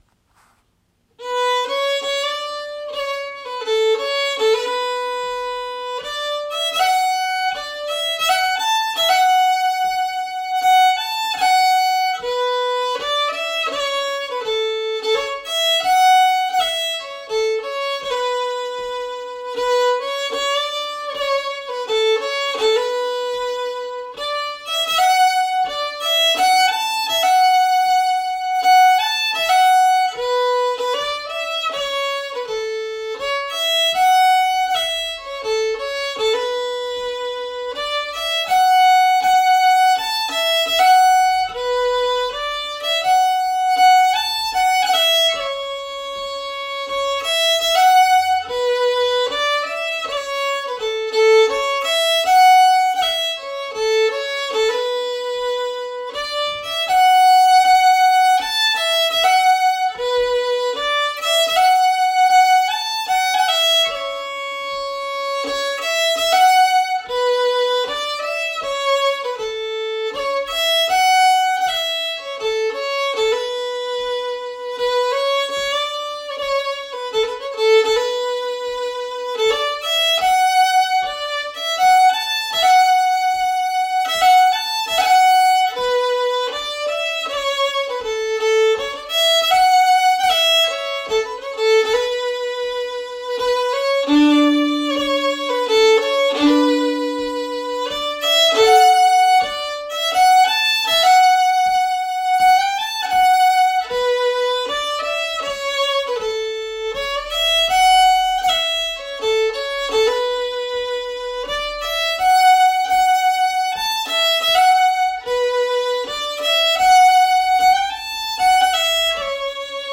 It is a ‘retreat march’ by the piper Duncan Johnstone, which means that unlike most marches, it is in 3/4 instead of 4/4.